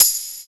118 TAMB.wav